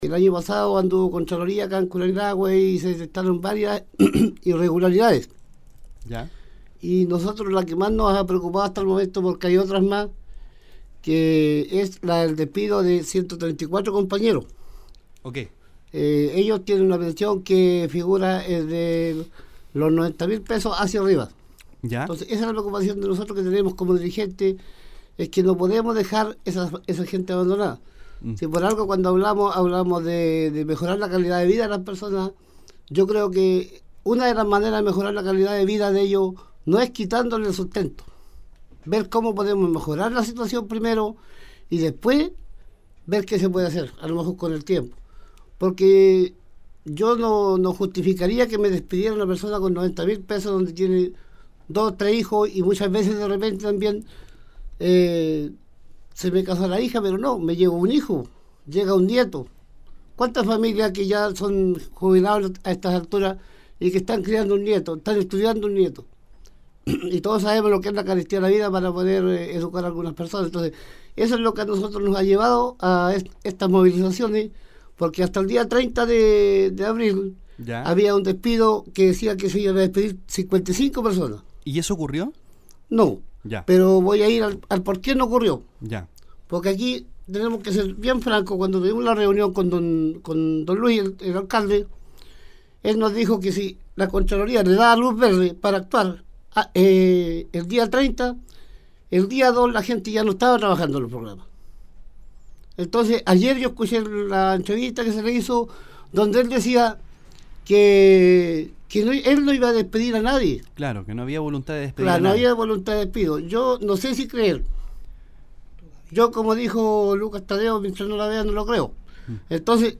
Revisa la entrevista realizada hoy en el programa «La Gran Mañana» a los dirigentes de los trabajadores PMU